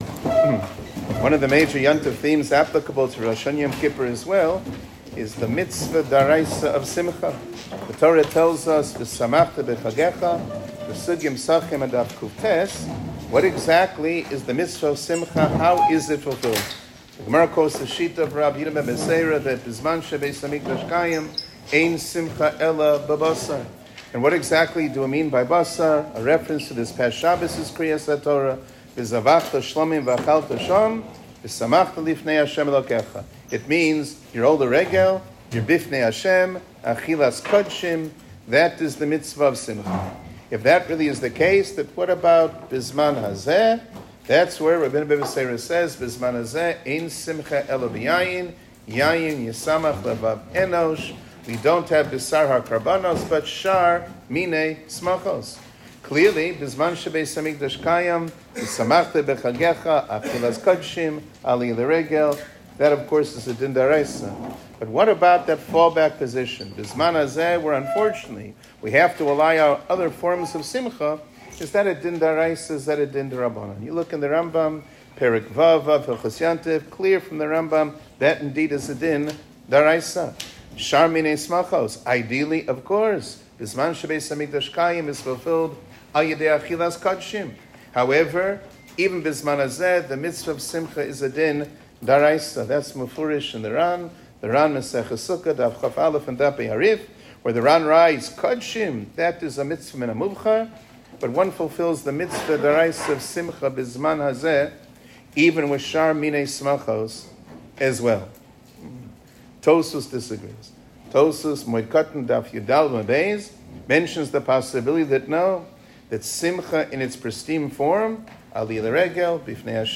שיעור כללי - שמחת יום טוב